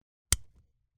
Lamp-Switch-On.mp3